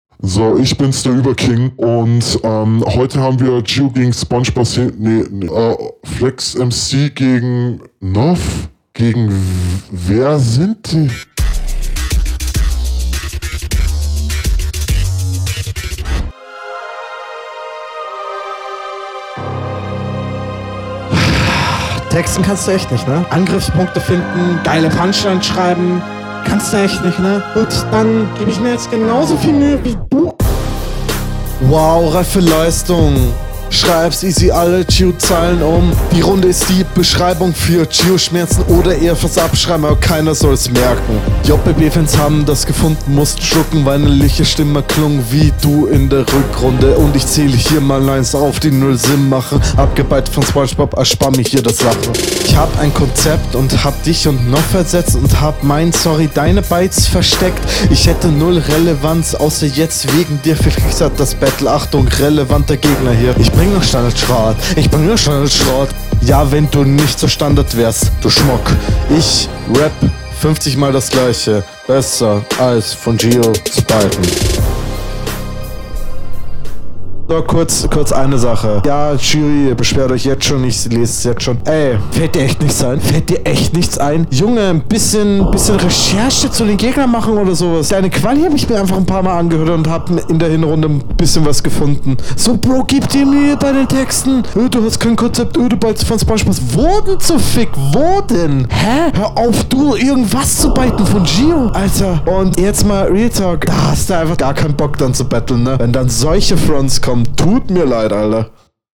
Auch du kannst noch deutlich am Flow und an der Reimtechnik arbeiten.